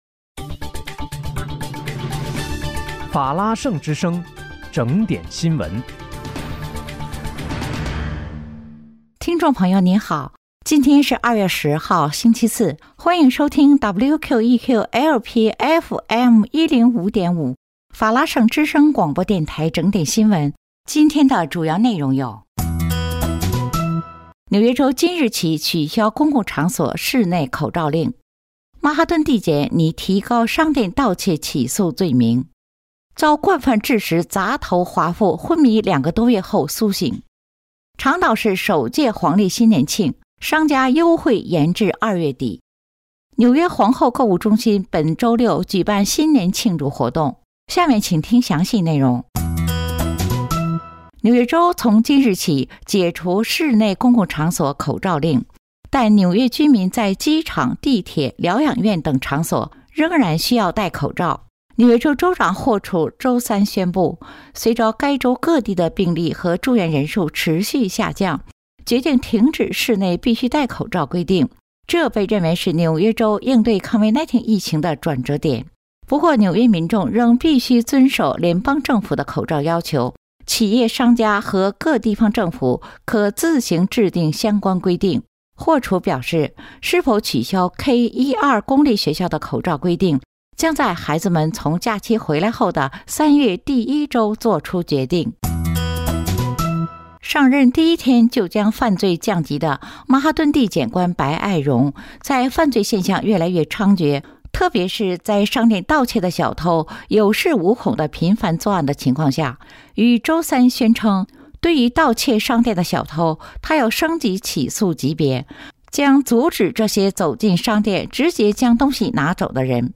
2月10日（星期四）纽约整点新闻
今天是2月10号，星期四，欢迎收听WQEQ-LP FM105.5法拉盛之声广播电台整点新闻。